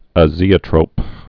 (ə-zēə-trōp, āzē-)